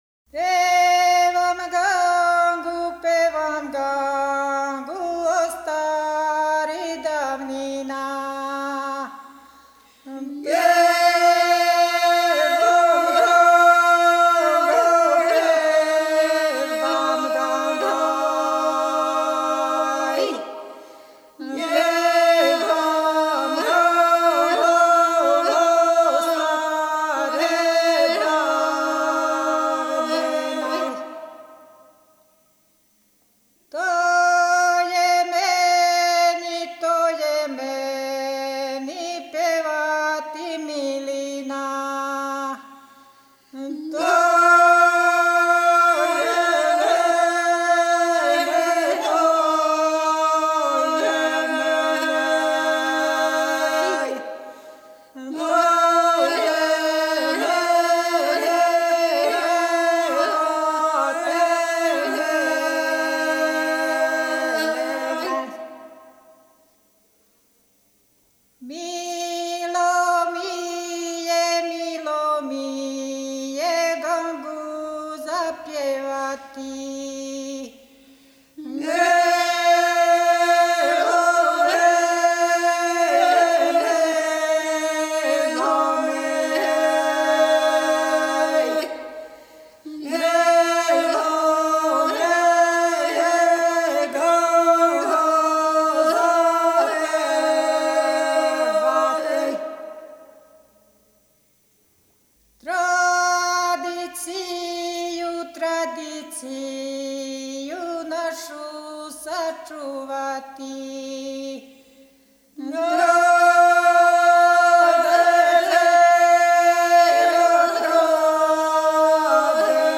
Снимци КУД "Др Младен Стојановић", Младеново - Девојачка и женска певачка група (6.4 MB, mp3) О извођачу Албум Уколико знате стихове ове песме, молимо Вас да нам их пошаљете .